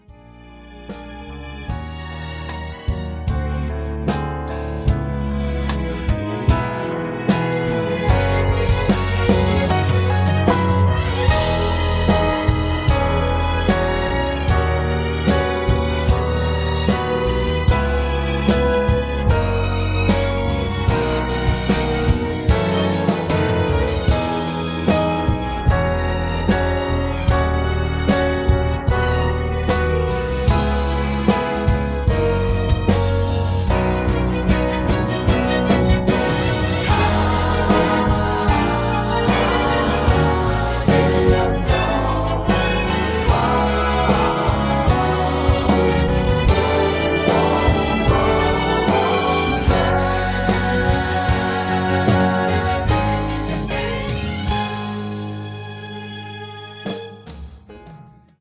음정 -1키
장르 가요 구분